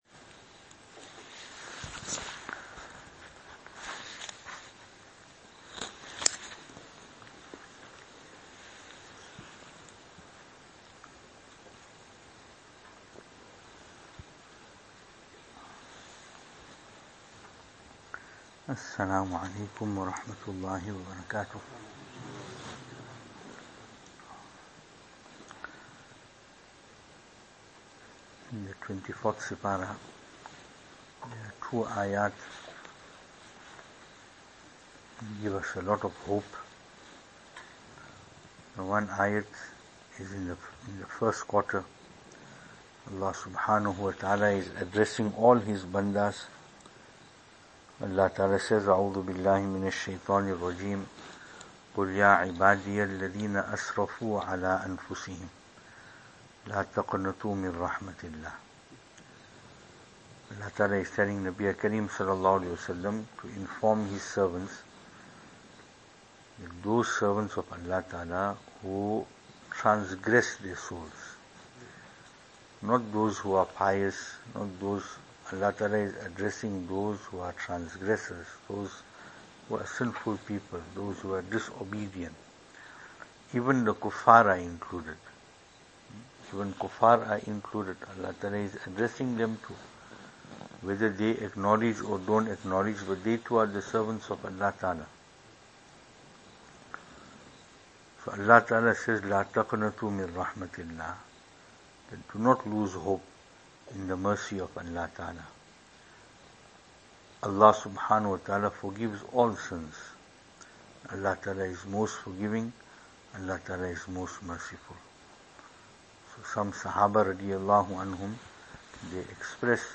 2023-04-15 After Taraweeh Advices 24th Night Venue: Albert Falls , Madressa Isha'atul Haq Series
Service Type: Ramadaan